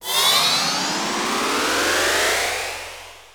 RISEPANNER.wav